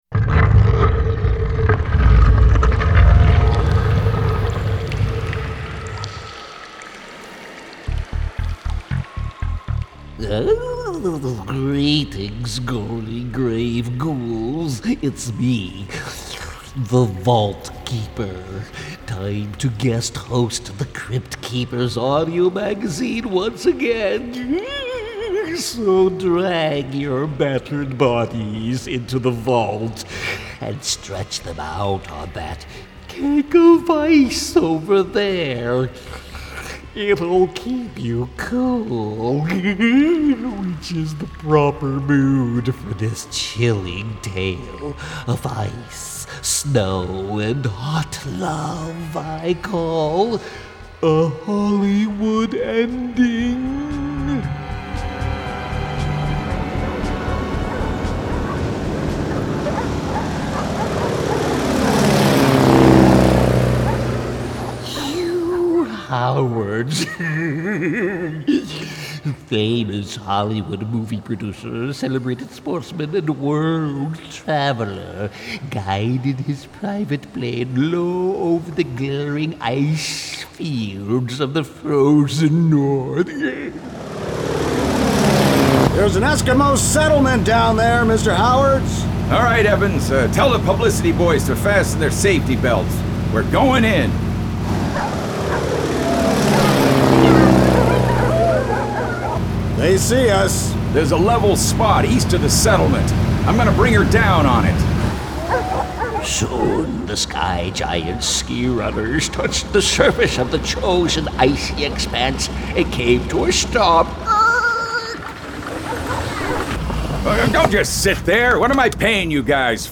We also love classic horror and pulp. Enjoy our audiotheater demo...